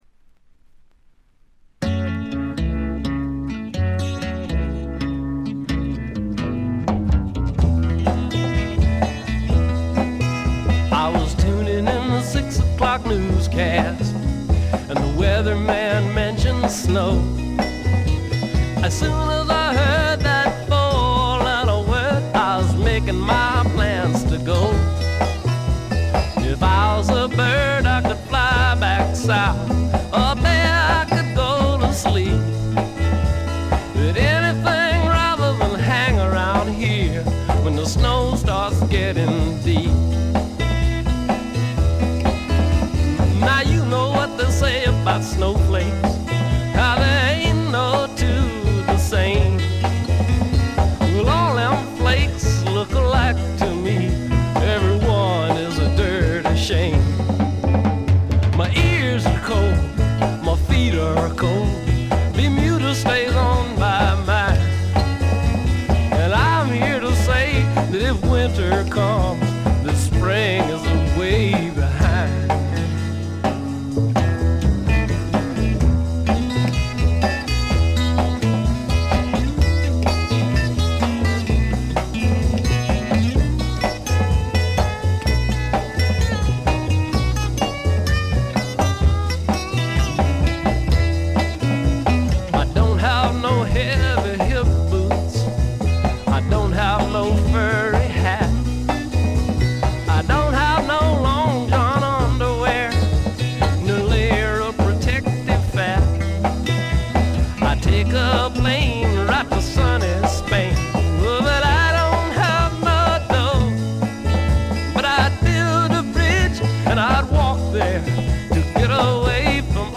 部分試聴ですが軽いチリプチ程度。
ロマンチシズムをたたえながらもメランコリックになり過ぎない、硬質な質感に貫かれたとても素敵なアルバム。
試聴曲は現品からの取り込み音源です。